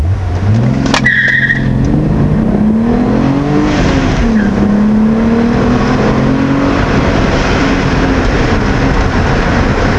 VW VR6 (WAV 218Ko)
vr6_launch.wav